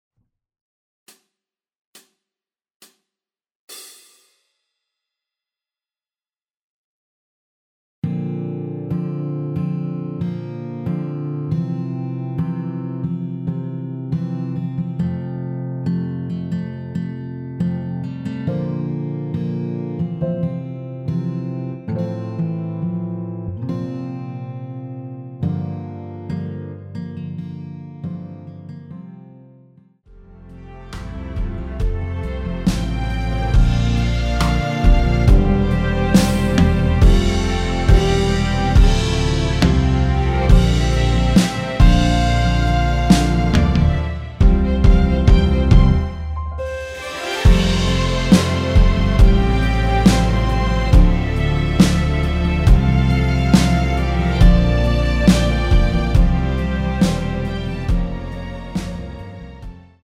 전주 없이 시작하는 곡이라서 시작 카운트 만들어놓았습니다.(미리듣기 확인)
원키에서(-3)내린 MR입니다.
앞부분30초, 뒷부분30초씩 편집해서 올려 드리고 있습니다.